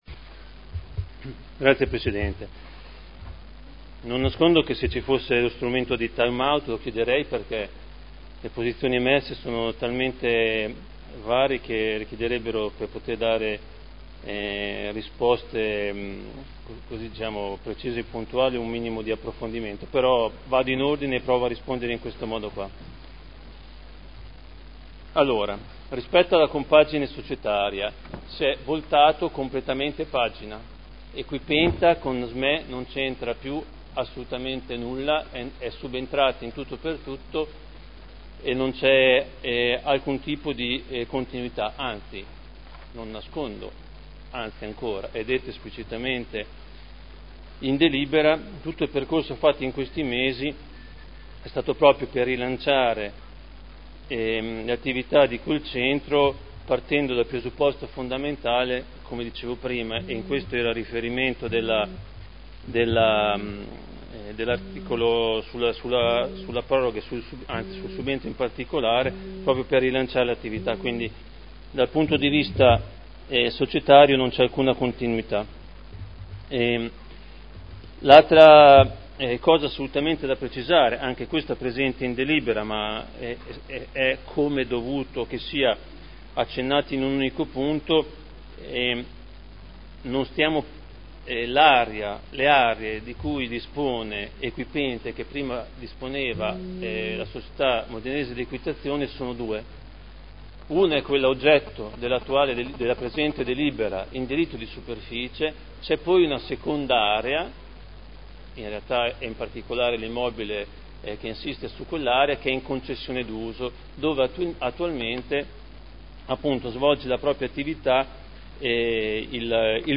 Seduta del 30/05/2011. Dibattito su proposta di deliberazione: Proroga e integrazione del diritto di superficie assegnato a Equipenta Srl per sport equestri in Via Contrada